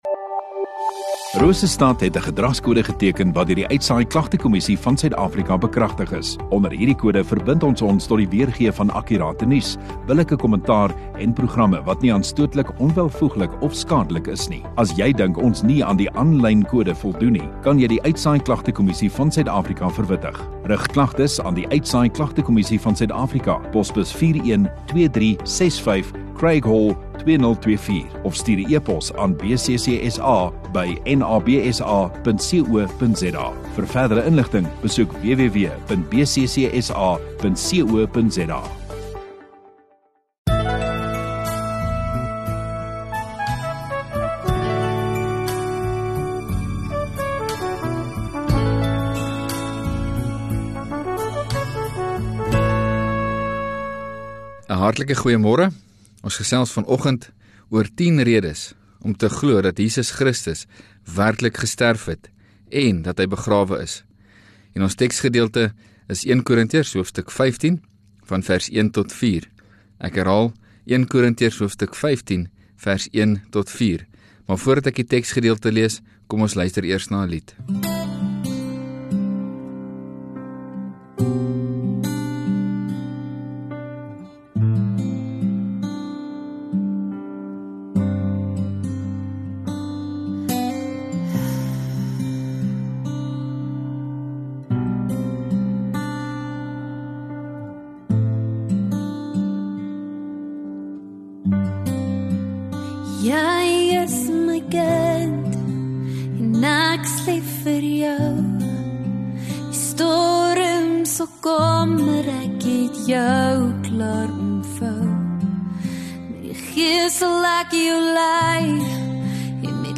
6 Apr Sondagoggend Erediens